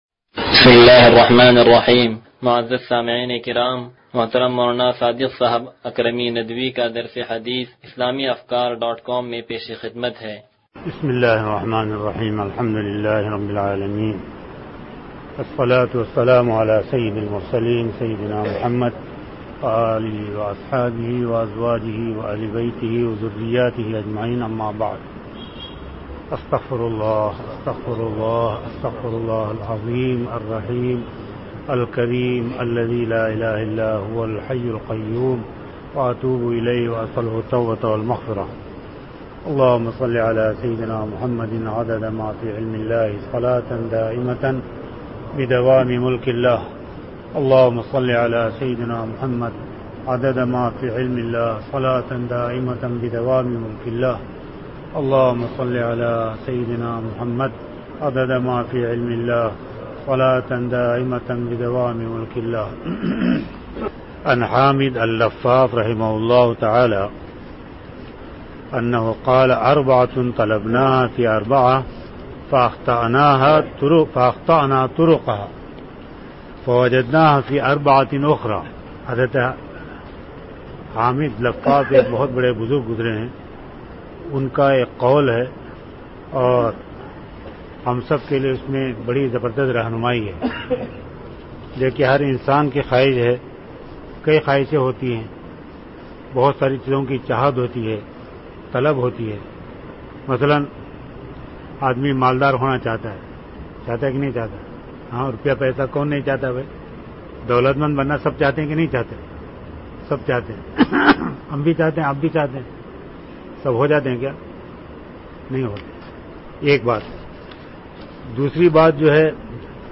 درس-حدیث-نمبر-0090.mp3